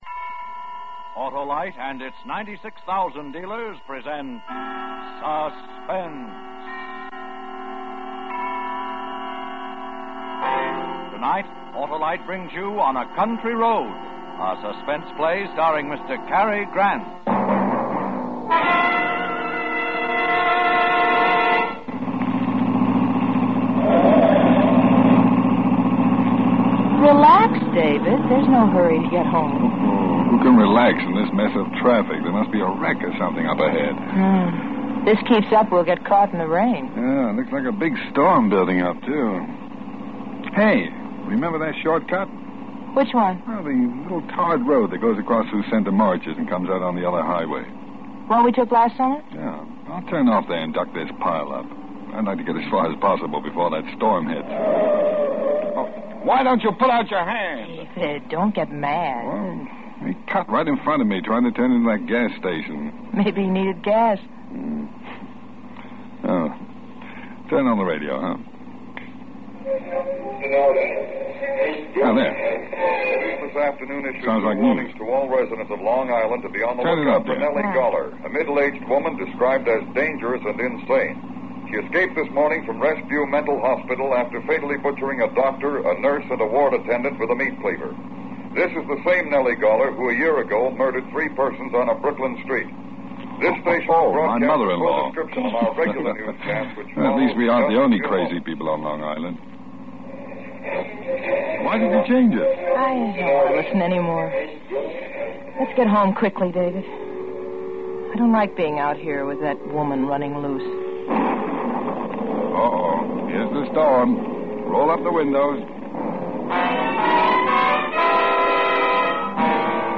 No music today but two radio dramas.
This entry was posted on May 5, 2012 at 1:19 pm and is filed under Old Time Radio.